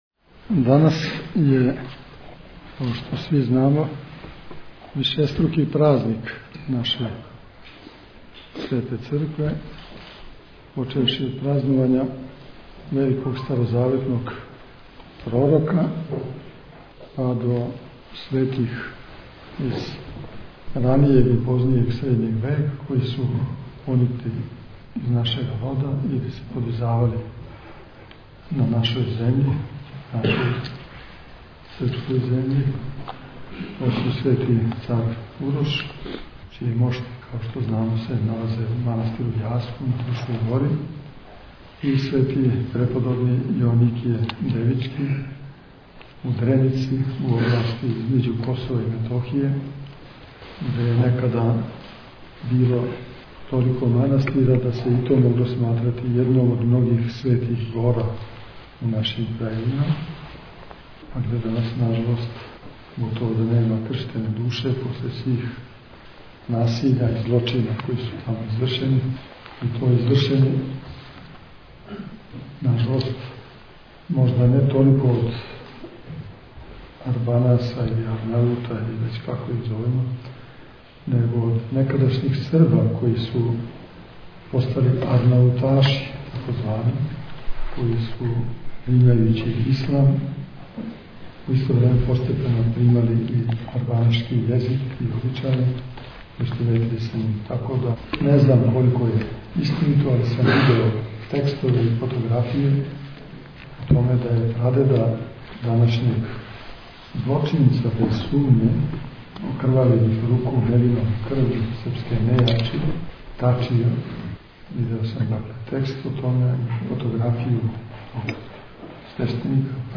На дан молитвеног спомена новоканонизованог угодника Божјег преподобног Порфирија Кавсокаливита, у недељу 15./2. децембра 2013. године, у свечаној дворани Гимназије Јован Јовановић Змај, о светом старцу Порфирију говорили су епископи бачки Иринеј и јегарски Порфирије.
Tagged: Предавања